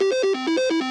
spin_loop1.wav